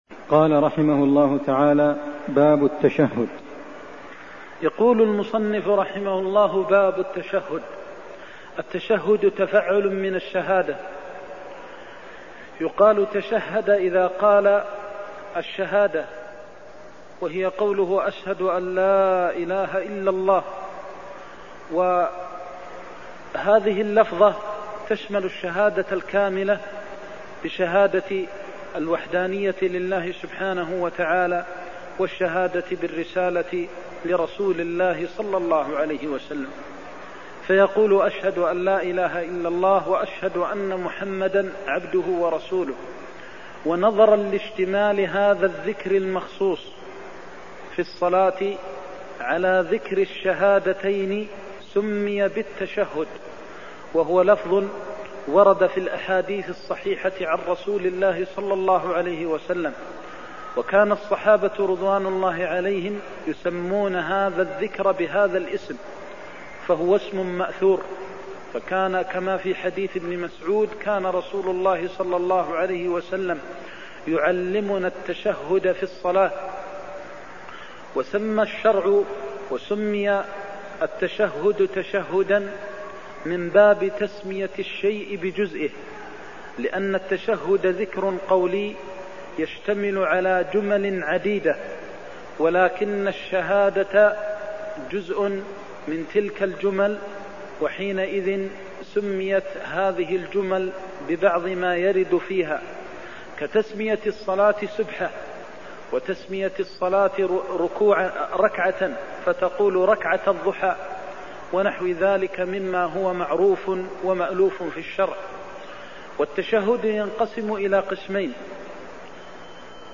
المكان: المسجد النبوي الشيخ: فضيلة الشيخ د. محمد بن محمد المختار فضيلة الشيخ د. محمد بن محمد المختار صفة التشهد (114)